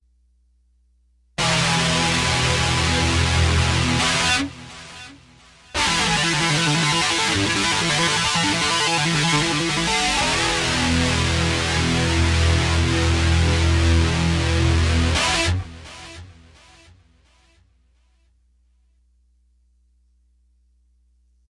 Tag: 140 bpm Trap Loops Synth Loops 1.15 MB wav Key : E